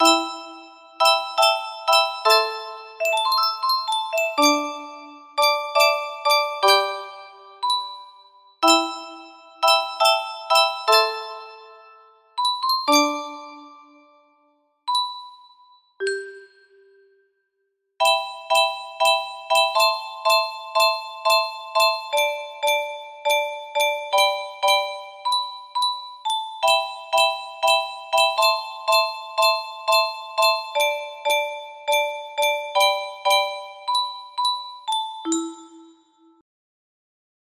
specially adapted for Muro Box 20